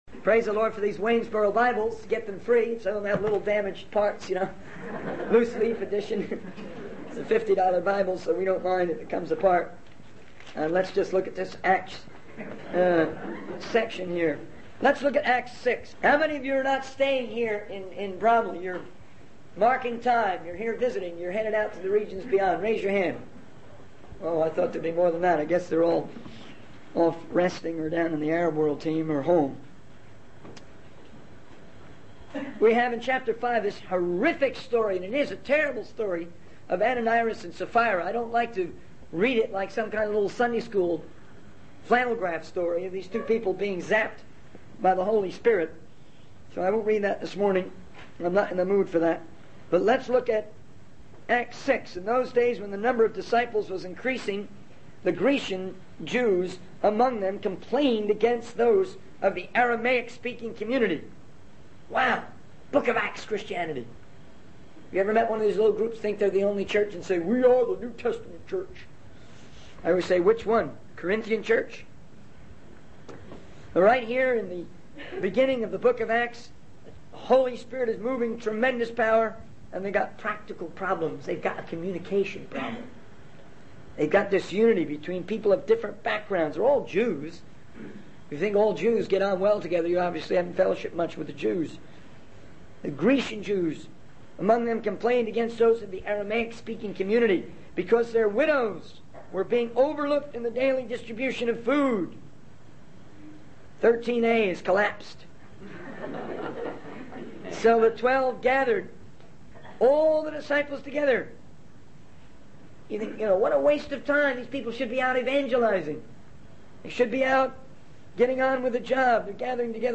In this sermon, the speaker discusses the importance of effective communication and prayer in the context of spreading the word of God. They mention the need for a new generator for the ship and the positive fellowship experienced during a conference. The speaker also highlights the success of a missions conference and the commitment of 60 individuals to world missions.